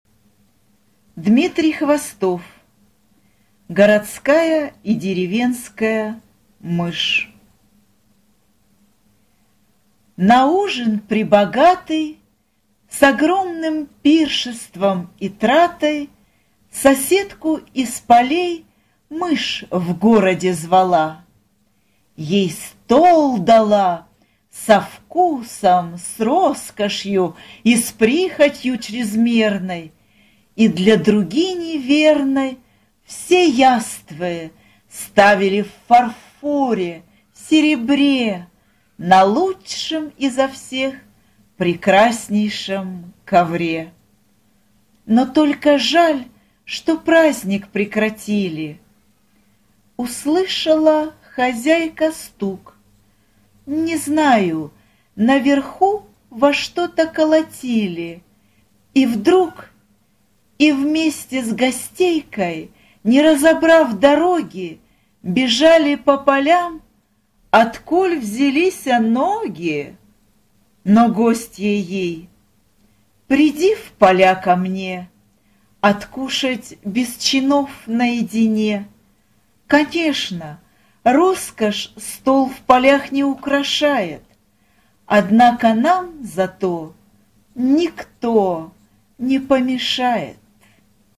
→Аудио книги →Аудио басни →Хвостов Дмитрий Иванович
Городская и деревенская мышь - аудиобасня Хвостова Дмитрия, которую вы можете слушать онлайн или скачать.